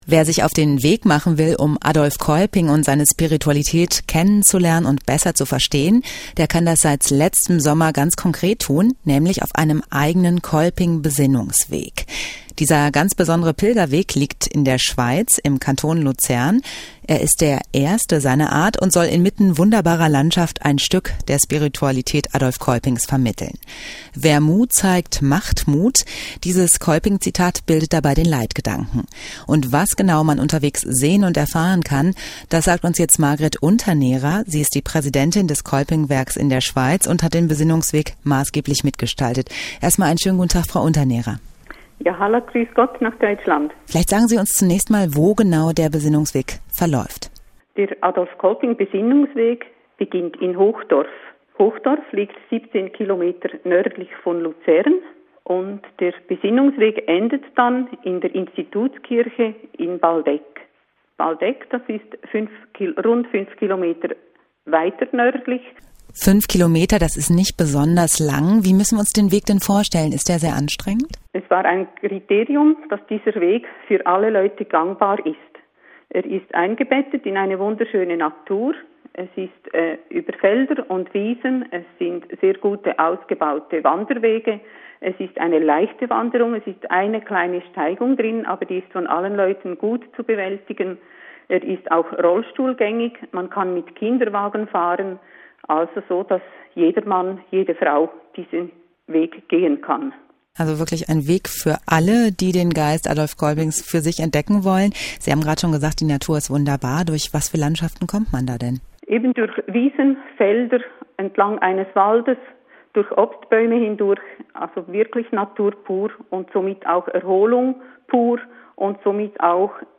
Radiobericht zum Adolph-Kolping-Besinnungsweg
domradioberichtbesinnungsweg.mp3